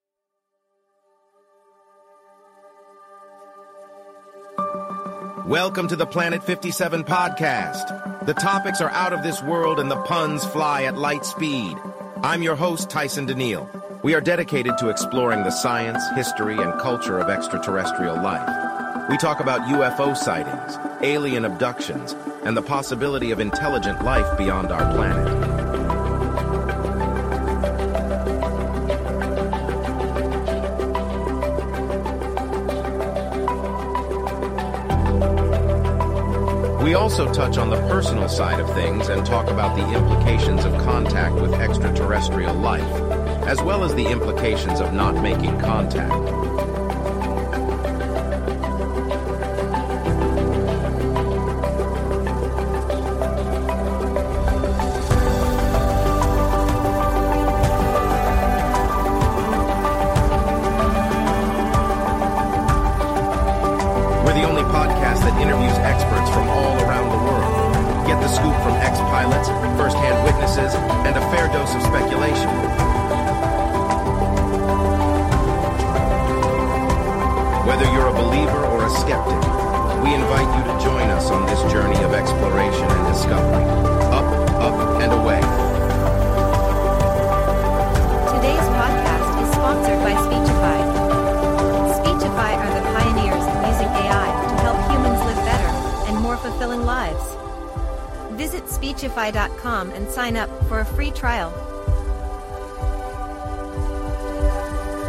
Basta fazer o upload do seu roteiro, adicionar música de fundo e exportar seu podcast de áudio.
vo-podcast.mp3